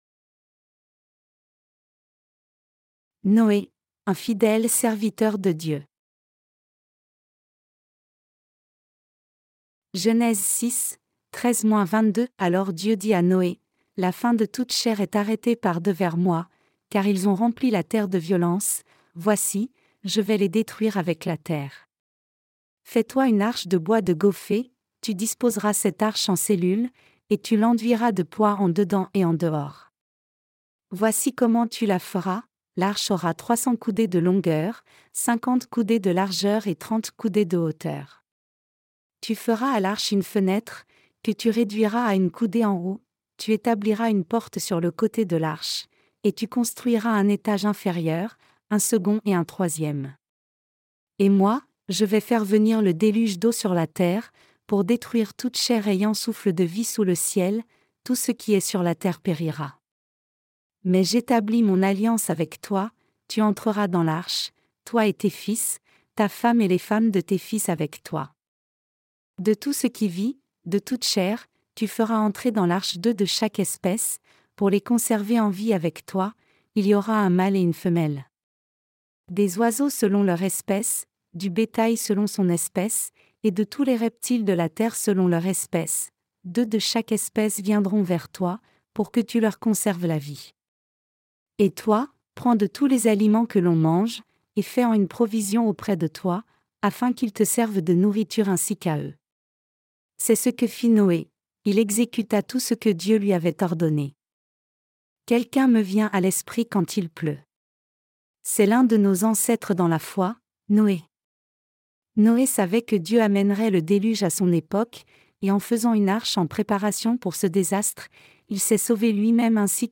Sermons sur la Genèse (V) - LA DIFFERENCE ENTRE LA FOI D’ABEL ET LA FOI DE CAÏN 15.